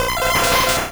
Cri de Noeunoeuf dans Pokémon Rouge et Bleu.